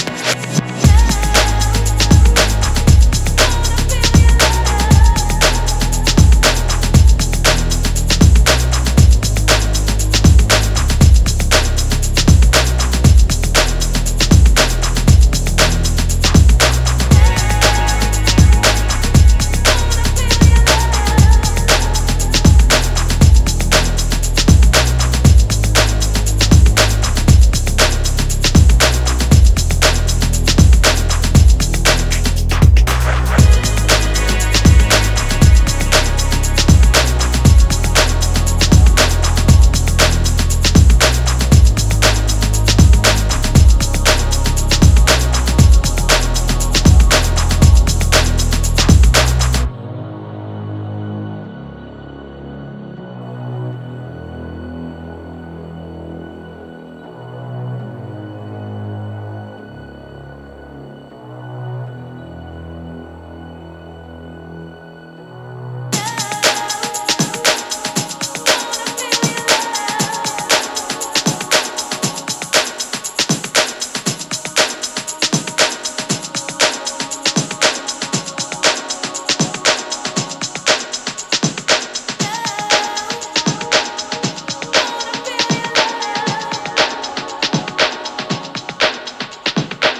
ジャンル(スタイル) DEEP HOUSE / TECH HOUSE